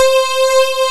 FUNK C6.wav